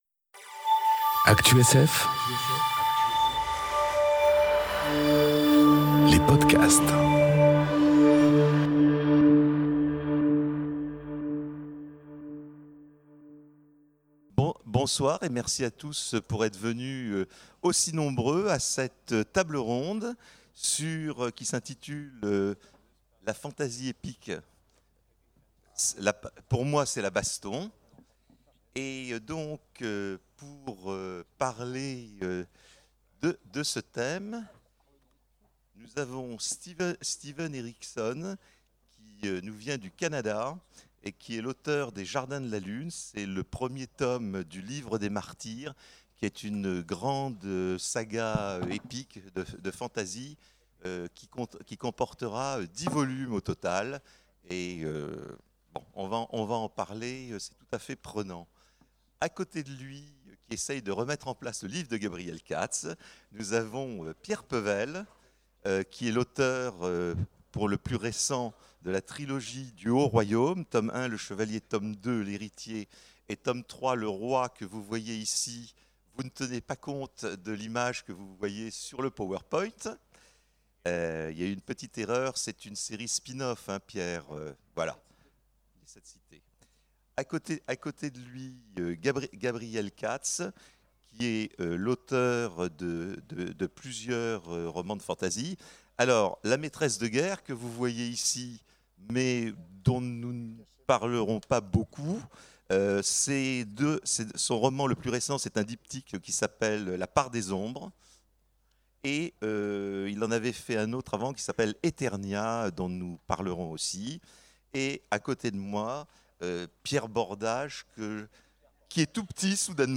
Conférence La fantasy épique... Pour moi c'est la baston ! enregistrée aux Imaginales 2018